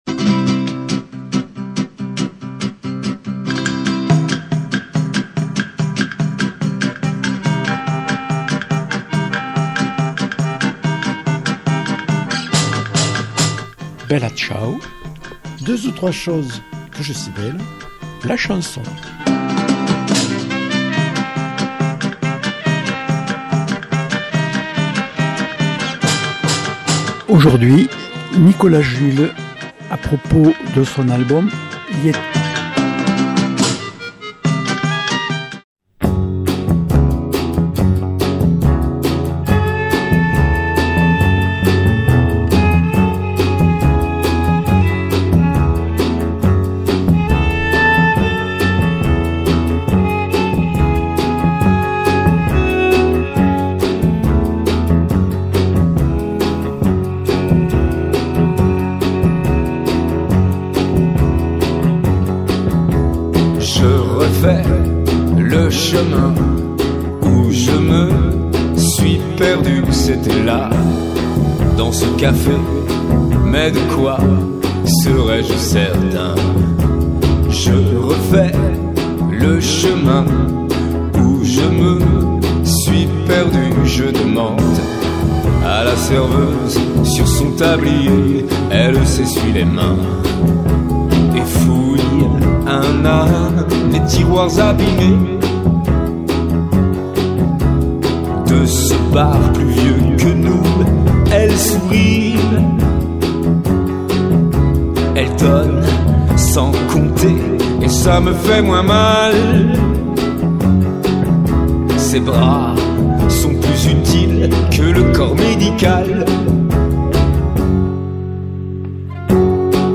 auteur-compositeur-musicien.